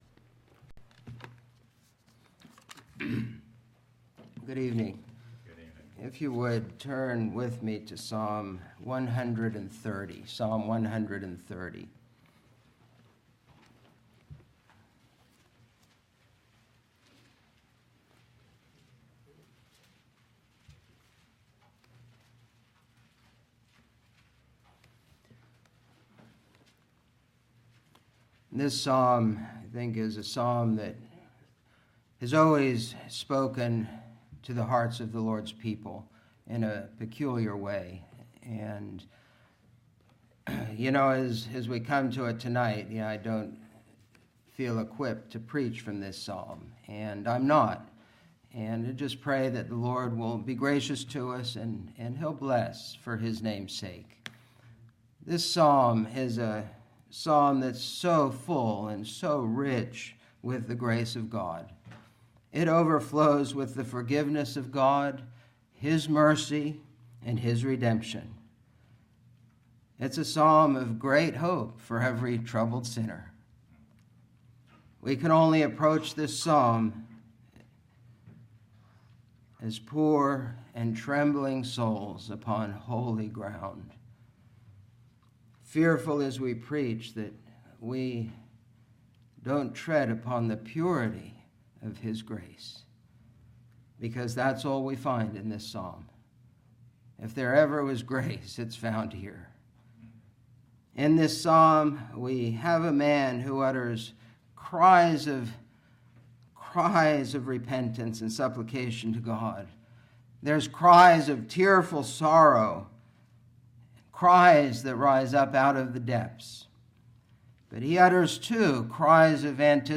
Broadcaster is Live View the Live Stream Share this sermon Disabled by adblocker Copy URL Copied!